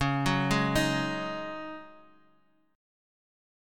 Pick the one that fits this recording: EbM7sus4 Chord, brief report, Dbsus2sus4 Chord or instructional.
Dbsus2sus4 Chord